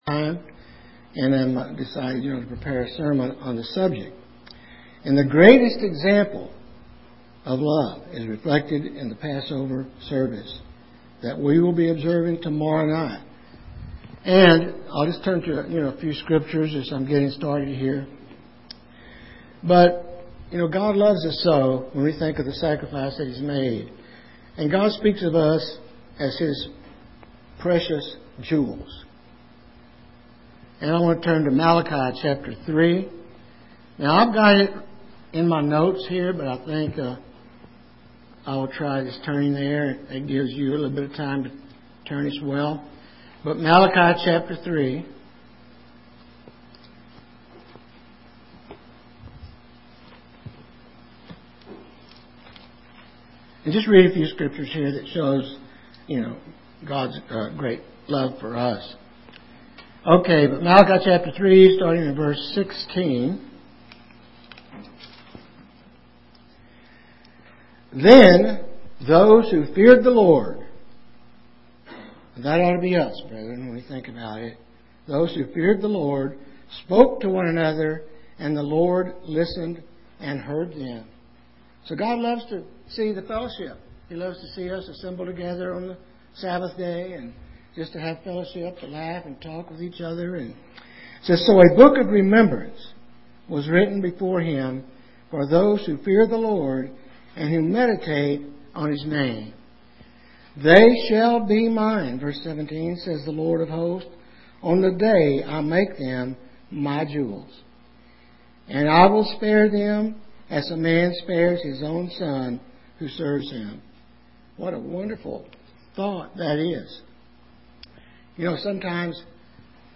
UCG Sermon Studying the bible?
Given in Lubbock, TX